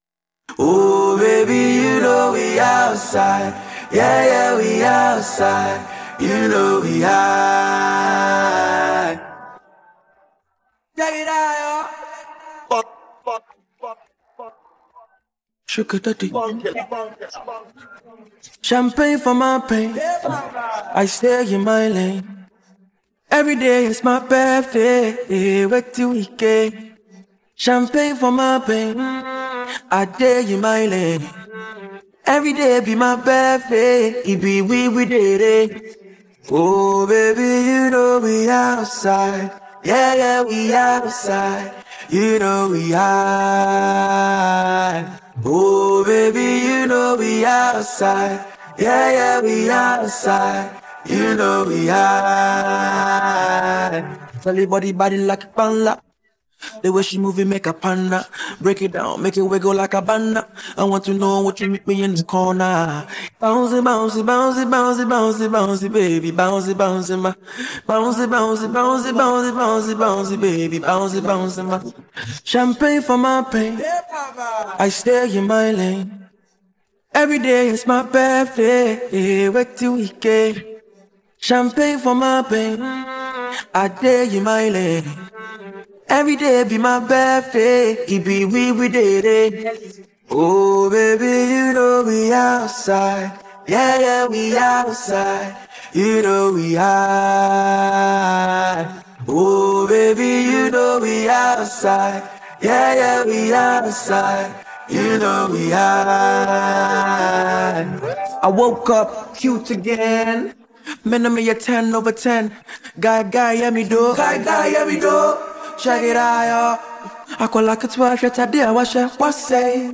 Enjoy the vocals
a free acapella mp3 to download.